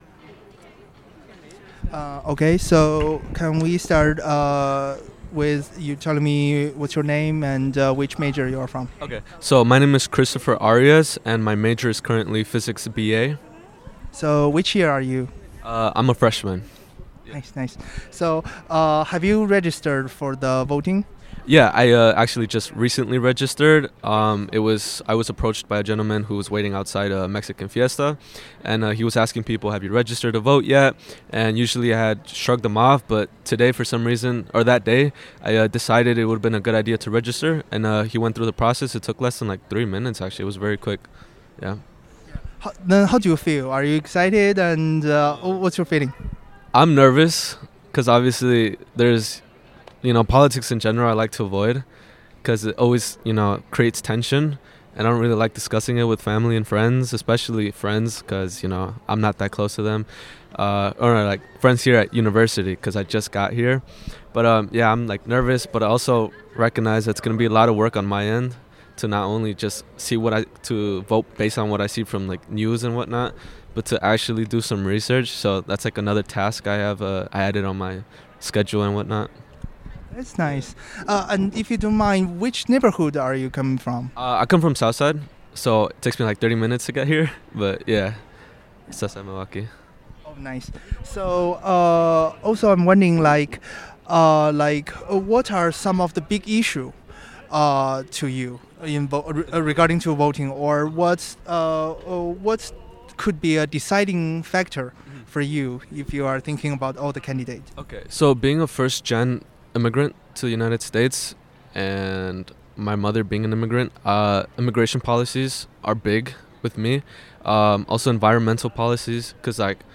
Location University of Wisconsin-Milwaukee